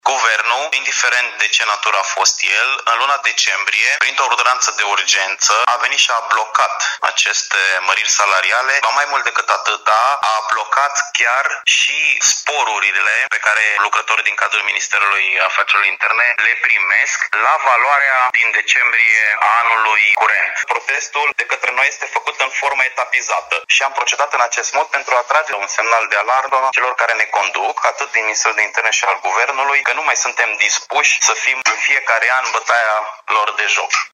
sindicalist-politisti.mp3